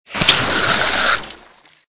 AA_squirt_neonwatergun.ogg